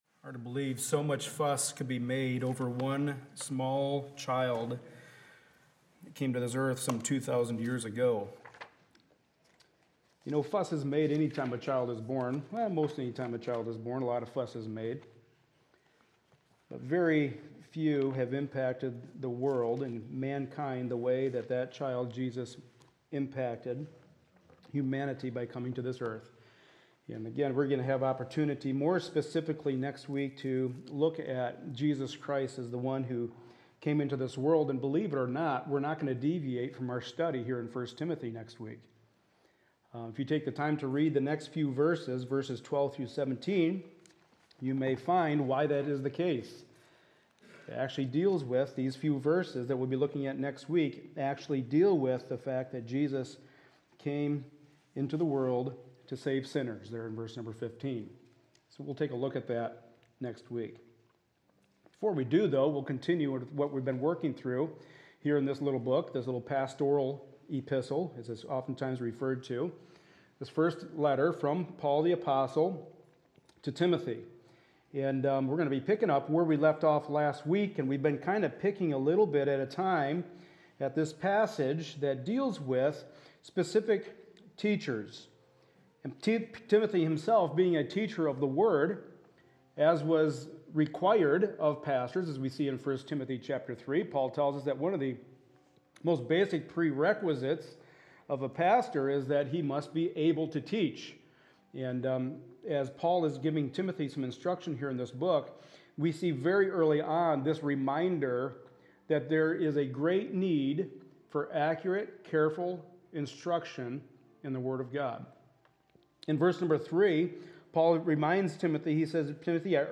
Service Type: Sunday Morning Service A study in the pastoral epistles